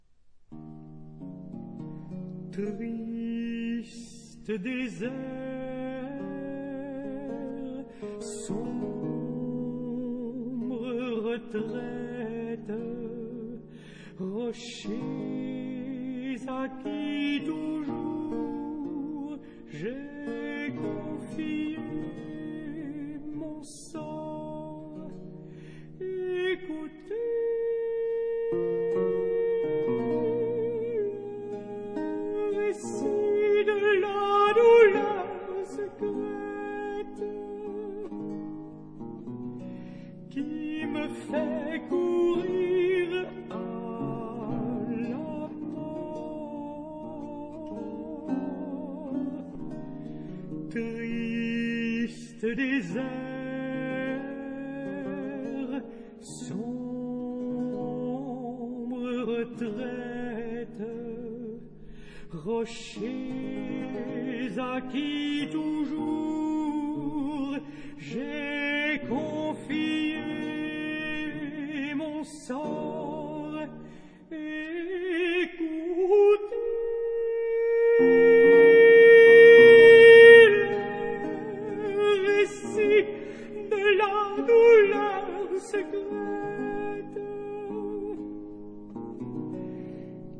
假聲魅力
假聲一點都不曖昧，也不庸俗。
那其中的情感，反而更加鮮明。